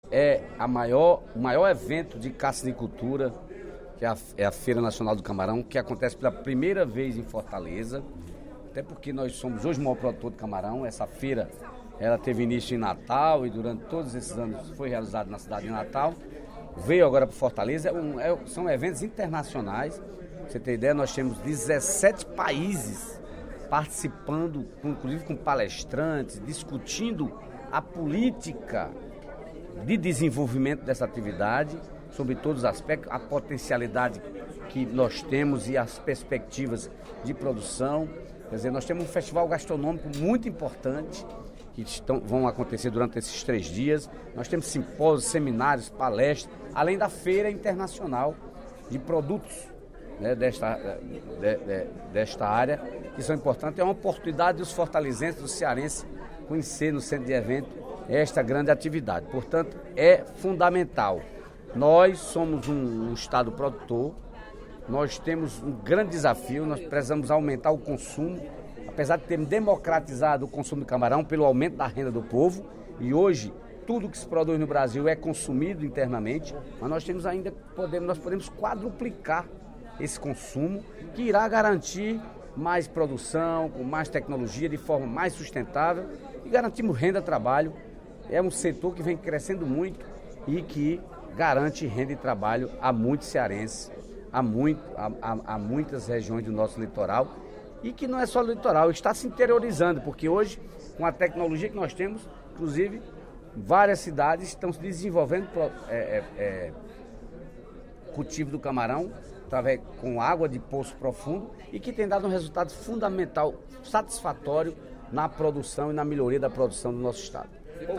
O deputado Dedé Teixeira (PT) destacou, durante o primeiro expediente da sessão plenária desta terça-feira (11/11), a realização da XI Feira Nacional do Camarão (FENACAM), que acontece de segunda (10/11) até quinta-feira (13/11) no Centro de Eventos do Ceará.